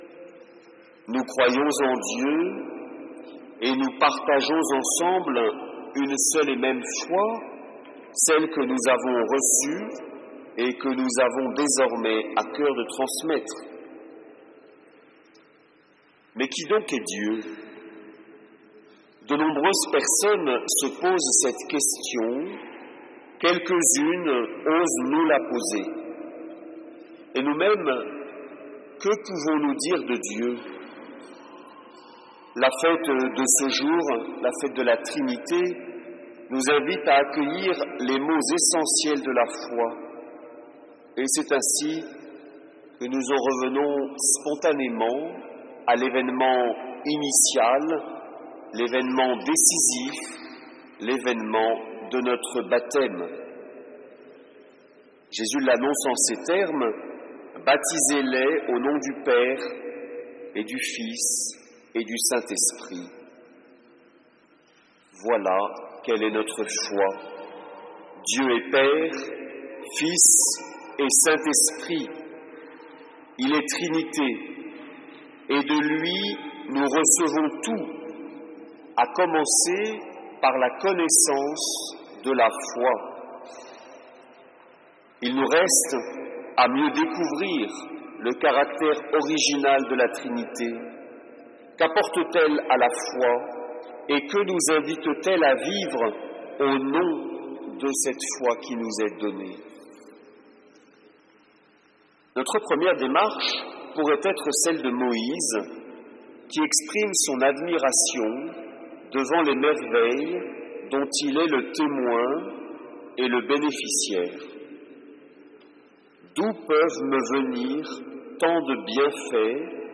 homélies